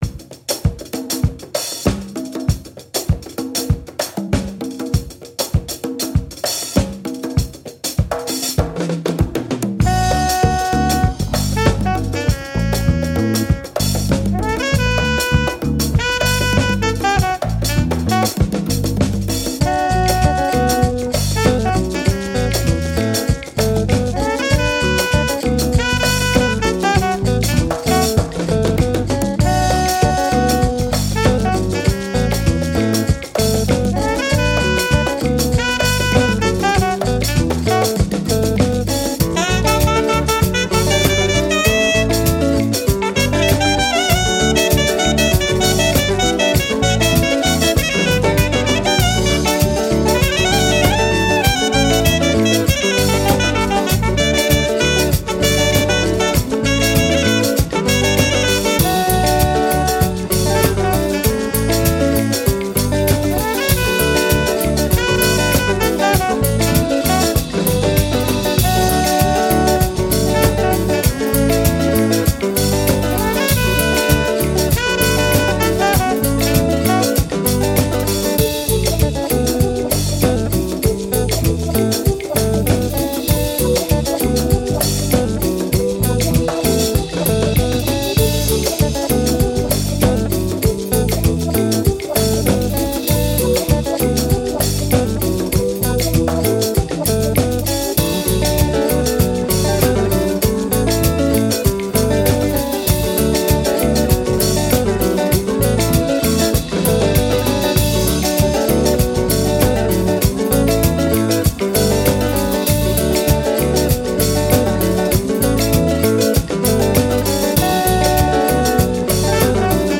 Afrobeat, Dance, Positive, Uplifting